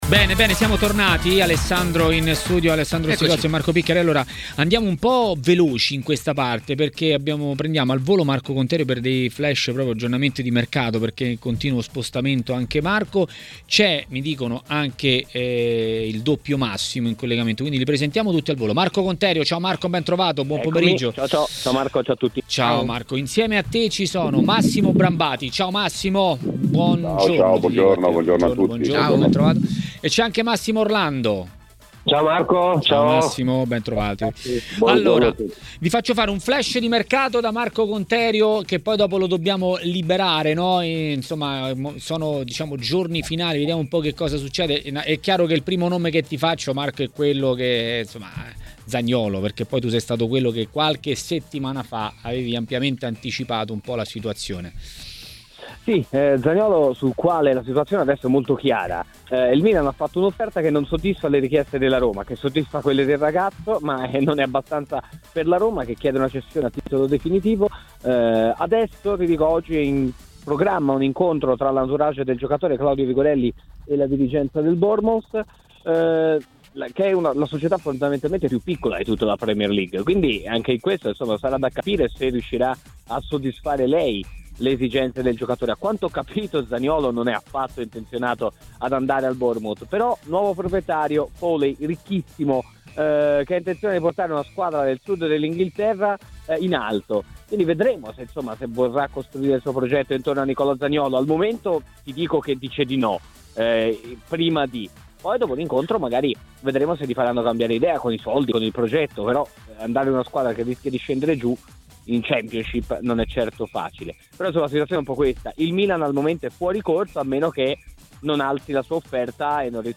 Zaniolo, Milan e Juventus: questi i temi affrontati in diretta a TMW Radio, durante Maracanà, dall'ex calciatore Massimo Orlando.